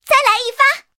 SU-26夜战攻击语音.OGG